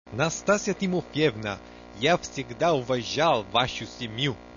Festa di fine corsi
In occasione della classica festa di conclusione dell' anno sociale, ben due allestimenti "plen air" nel cortile di Palazzo Rinuccini: